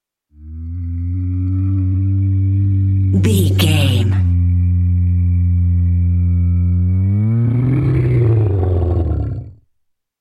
Dinosaur call growl close monster
Sound Effects
scary
ominous
eerie